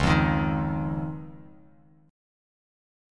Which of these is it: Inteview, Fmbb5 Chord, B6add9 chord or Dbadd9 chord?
Dbadd9 chord